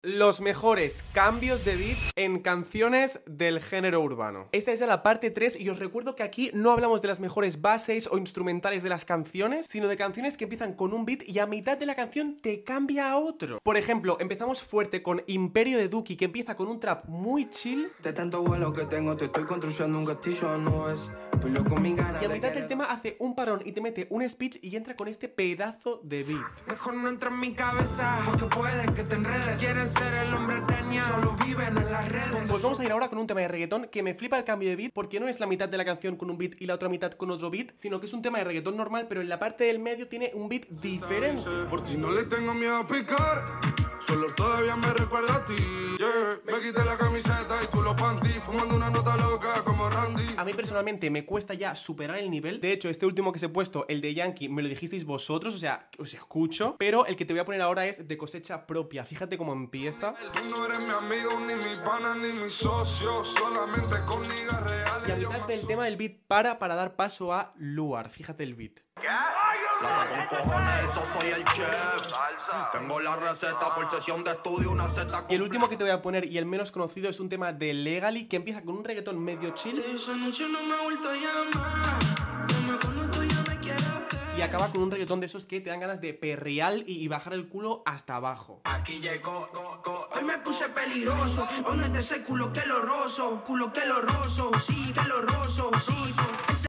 del género urbano